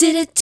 Di Dit 120-E.wav